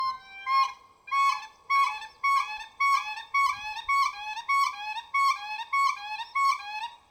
Unison Call | A duet performed by a pair, to strengthen their bond and protect their territory.
Siberian-Crane-Unison.mp3